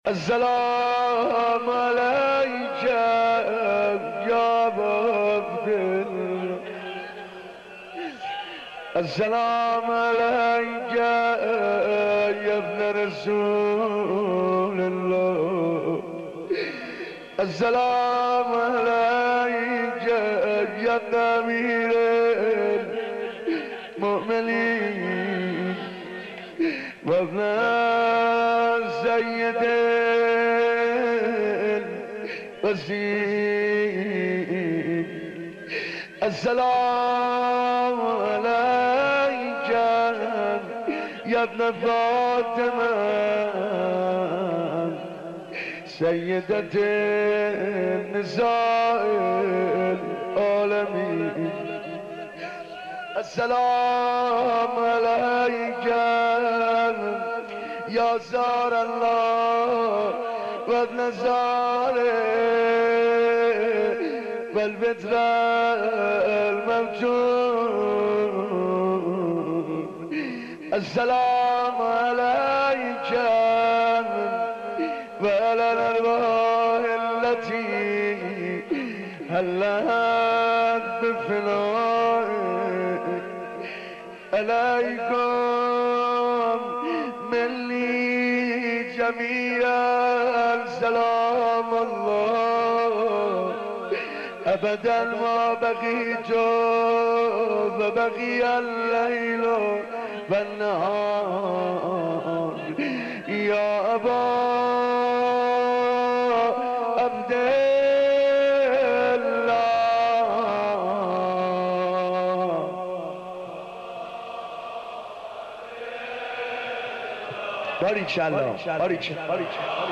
این مجلس بر میگرده به سال 1383 که در شب بیست و دوم ماه مبارک رمضان توسط مداح خوبمون حاج عبدالرضا هلالی قرائت شده.
helali-ashoora.mp3